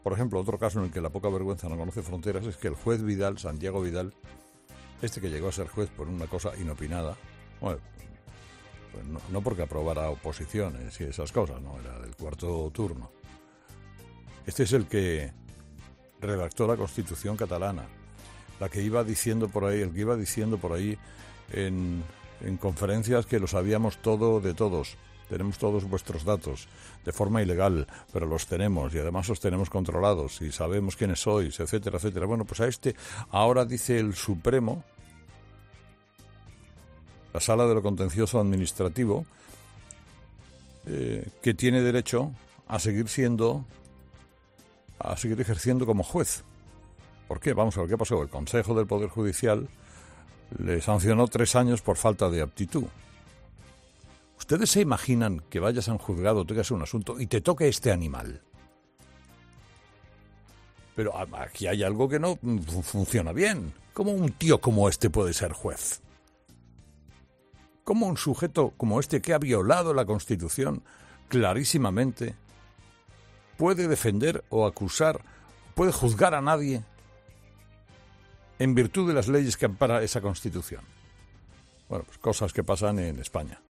El enfado de Herrera al conocer que el juez que elaboró la Constitución catalana podrá seguir ejerciendo
En su monólogo de este miércoles, Herrera se ha mostrado muy contrariado por la decisión del Supremo.